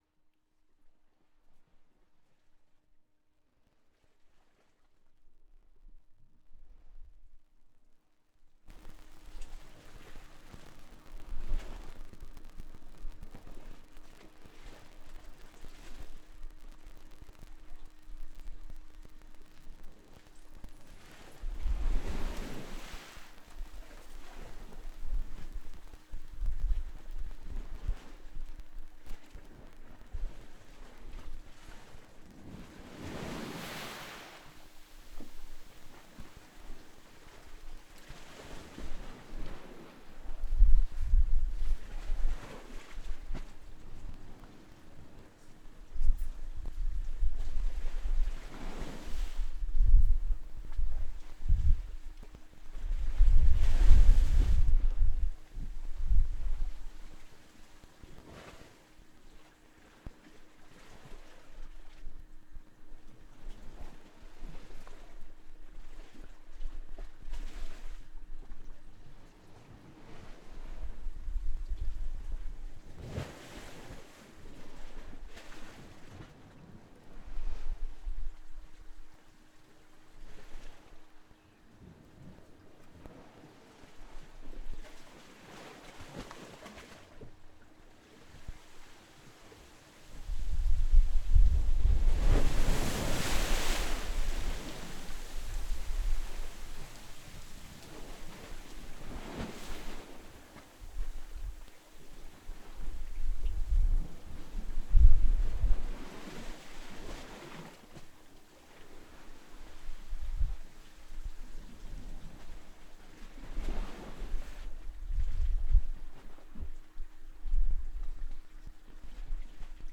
Zoom H6 (24 Bit, 96 kHz)
mit Rode NT4 Stereo-Kondensator Mikrofon
erste Aufnahme ohne Windschutz, 2. mit, dann plötzlich Regen
01 Wellen (Rode NT4).flac